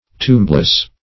Tombless \Tomb"less\, a.
tombless.mp3